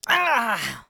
sfx_action_hurt_kid_03.wav